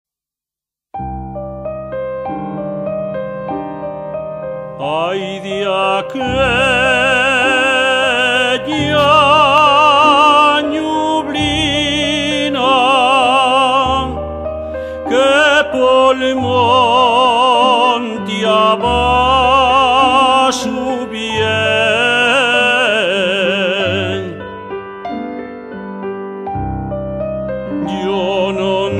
Tonada lírica popular asturiana.
Piano